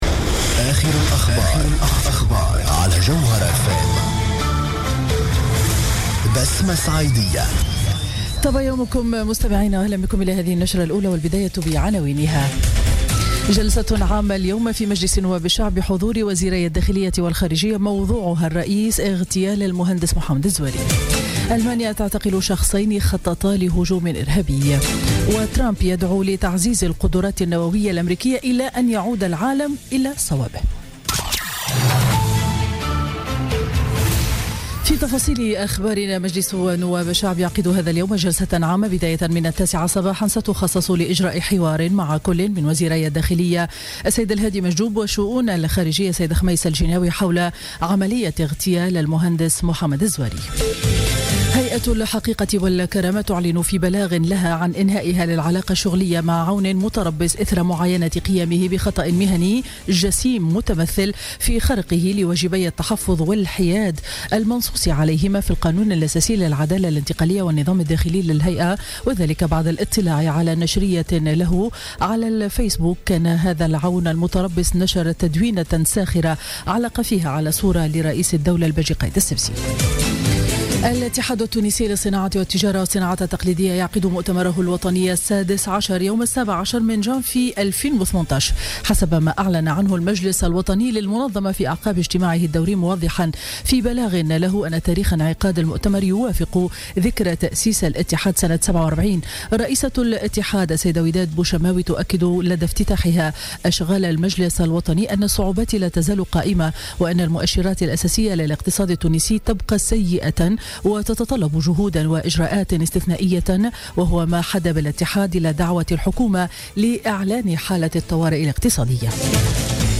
نشرة أخبار السابعة صباحا ليوم الجمعة 23 ديسمبر 2016